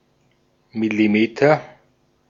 Ääntäminen
Ääntäminen Paris: IPA: [mi.li.mɛtʁ] France (Paris): IPA: /mi.li.mɛtʁ/ Haettu sana löytyi näillä lähdekielillä: ranska Käännös Konteksti Ääninäyte Substantiivit 1.